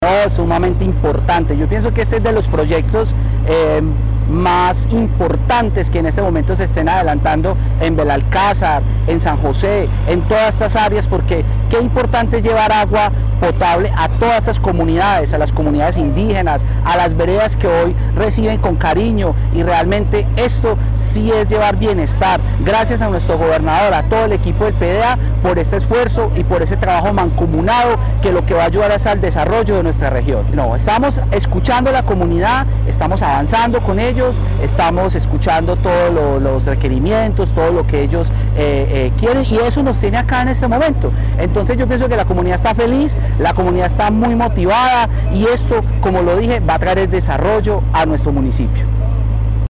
Fabio Andrés Ramírez Giraldo, alcalde de Belalcázar
Fabio-Andres-Ramirez-Giraldo-alcalde-de-Belalcazar.mp3